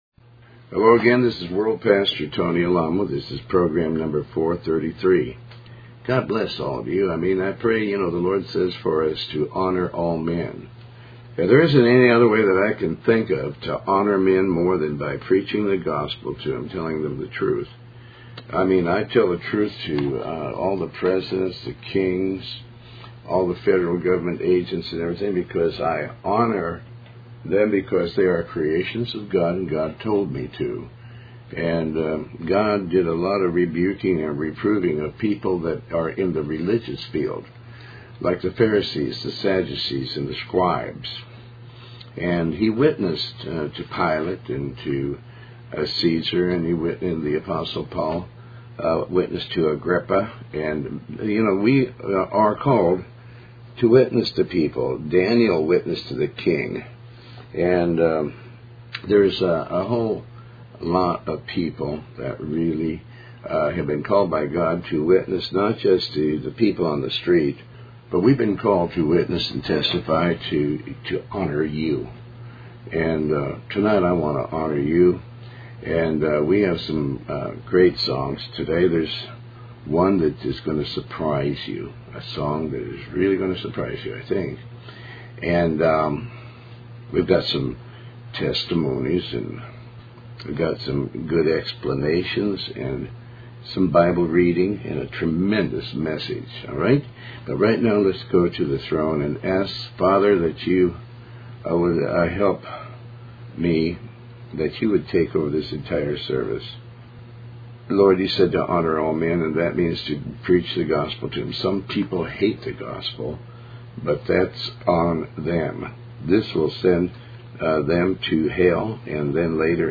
Tony Alamo Talk Show